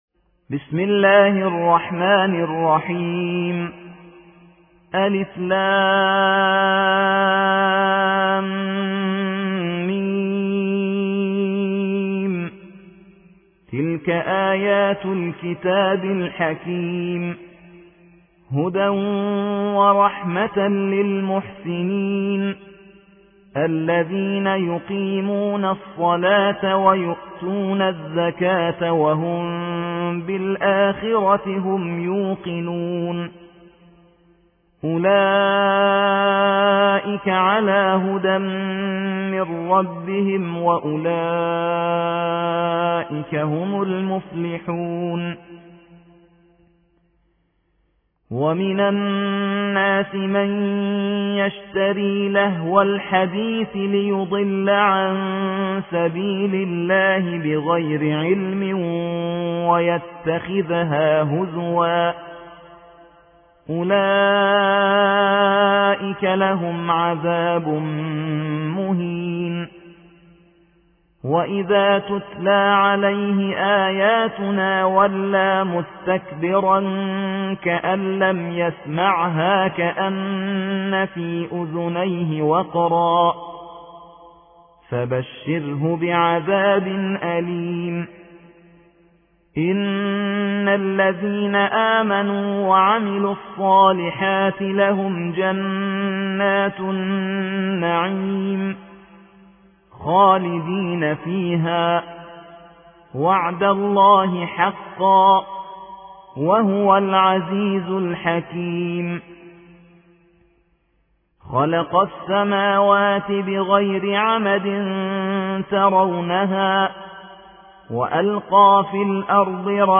31. سورة لقمان / القارئ